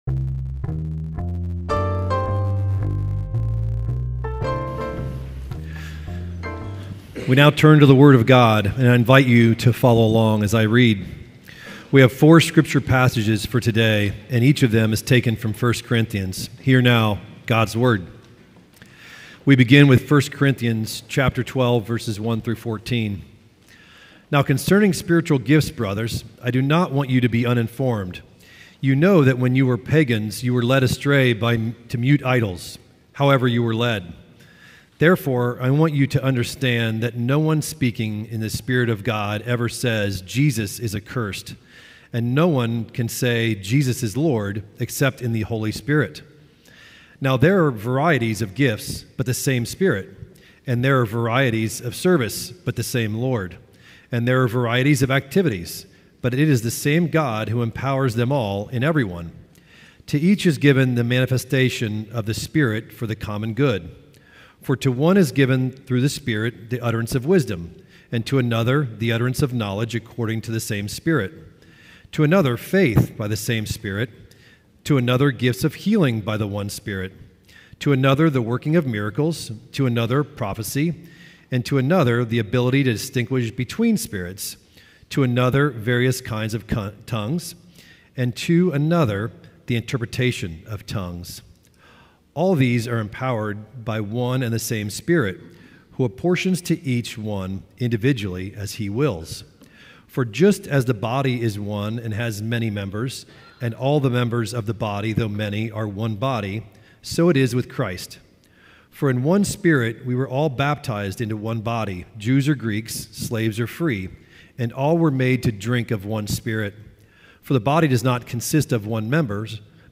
Passage: 1 Corinthians 12:1-14, 27-31; 13:1-3; 14:1-5 Service Type: Sunday Worship